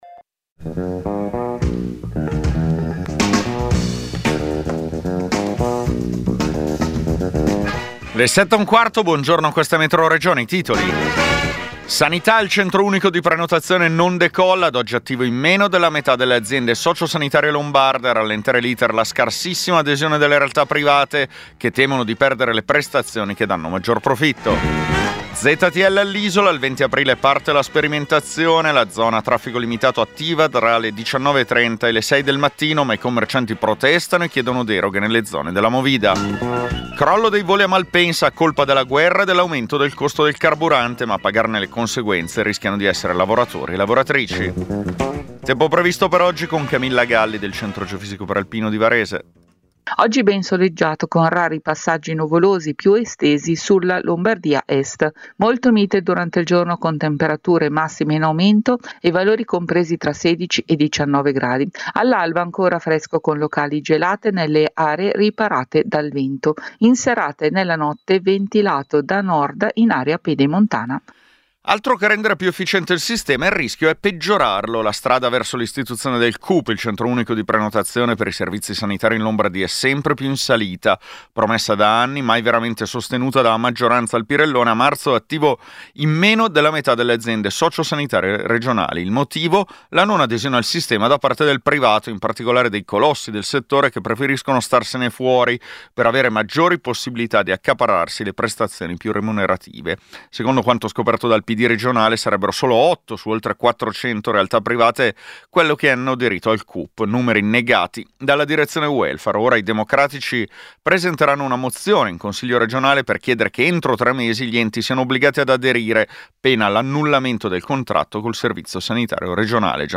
Metroregione è il notiziario regionale di Radio Popolare.